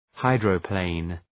Προφορά
{‘haıdrə,pleın}